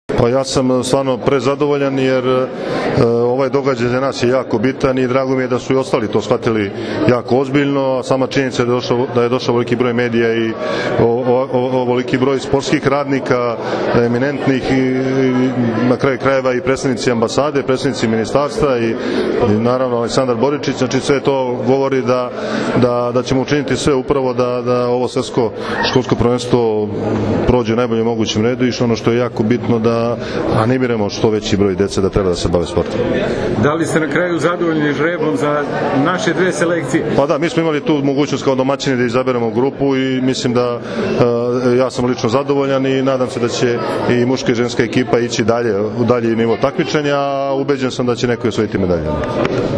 U Palati Srbija danas je obavljen žreb za Svetsko školsko prvenstvo u odbojci, koje će se odigrati od 25. juna – 3. jula u Beogradu.
IZJAVA